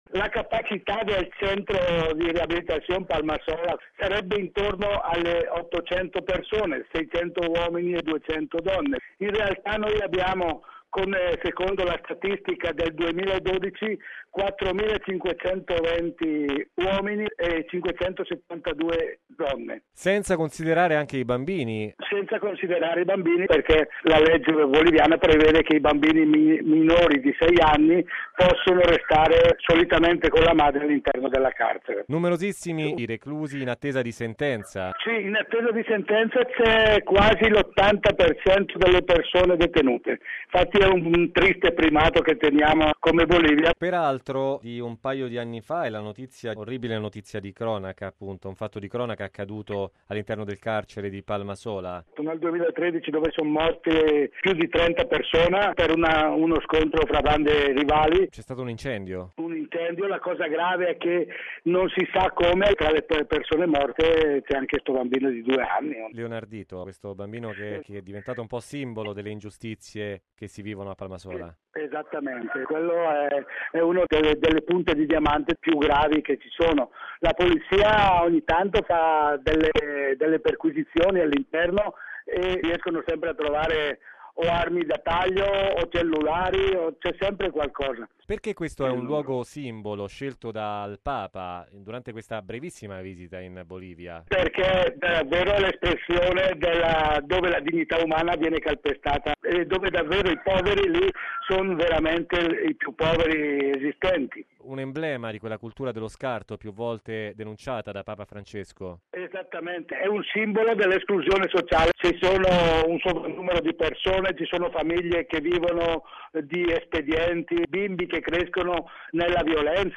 Bollettino Radiogiornale del 05/07/2015